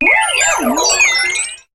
Cri de Lampignon dans Pokémon HOME.